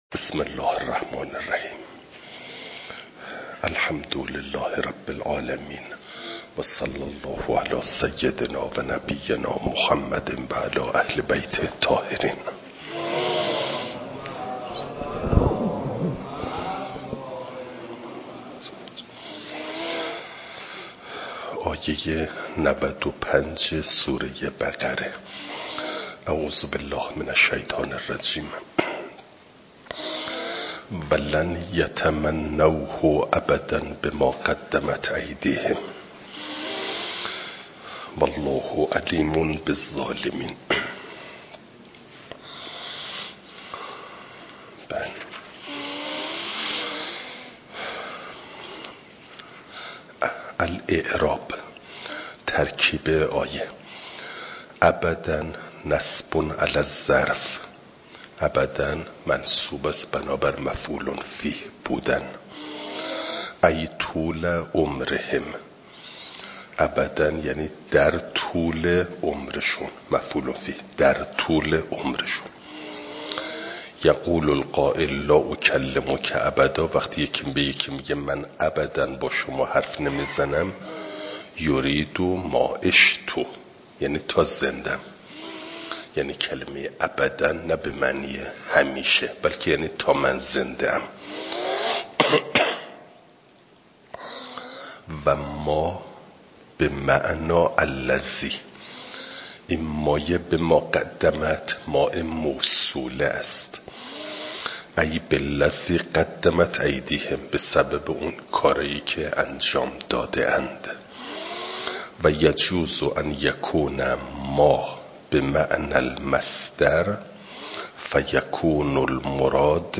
فایل صوتی جلسه نود و نهم درس تفسیر مجمع البیان